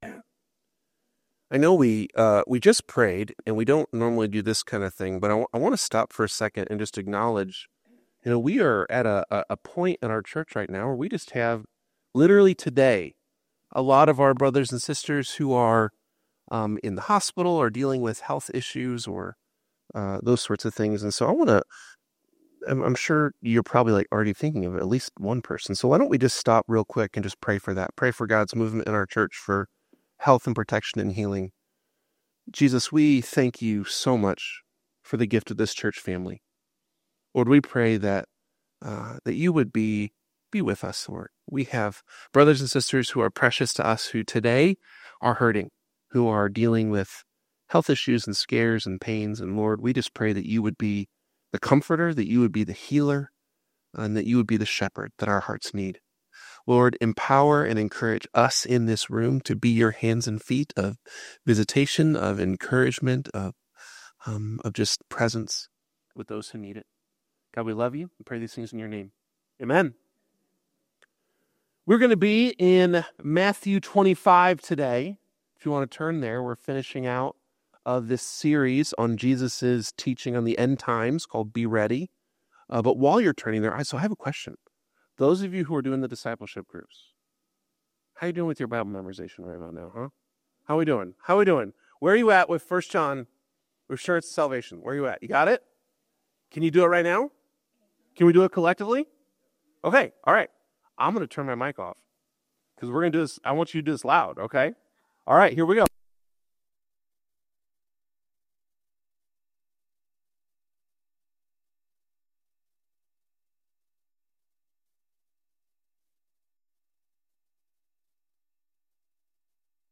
The sermon emphasizes the importance of living out our faith by serving those in need, reflecting the transformative power of the Gospel in our lives.